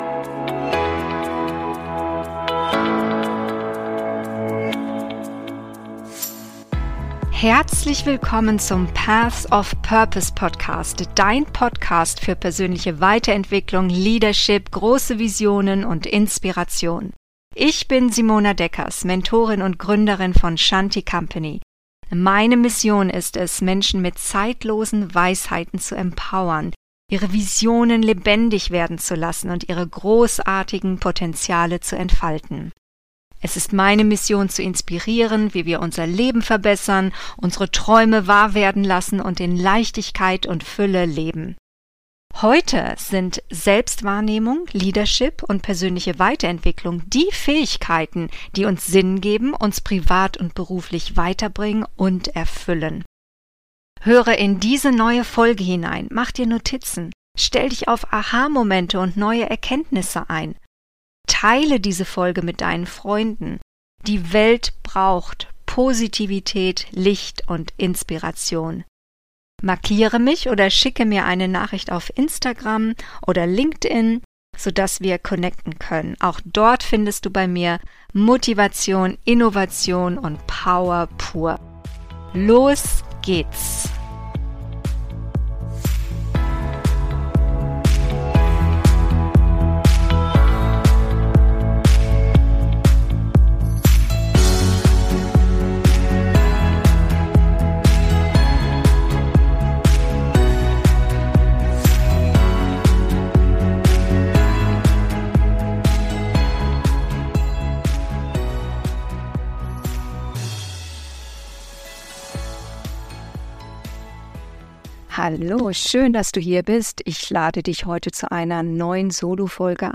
It's lonely at the top! - Solofolge